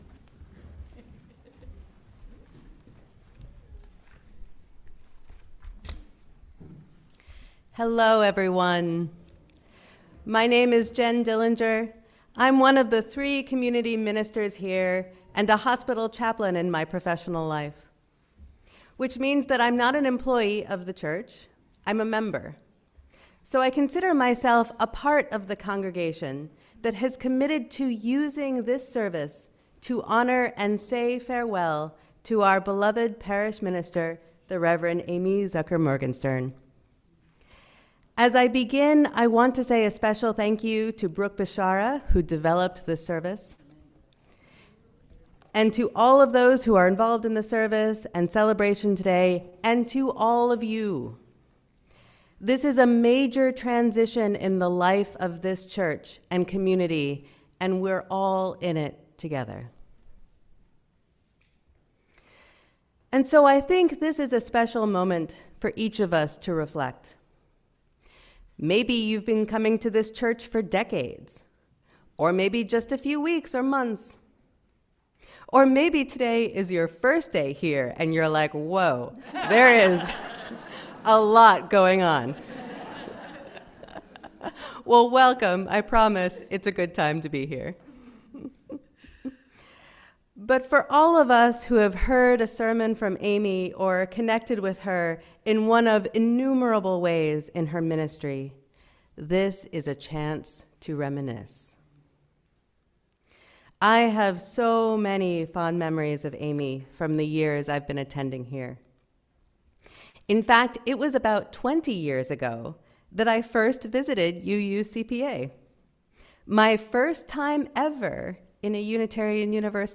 A special send-off service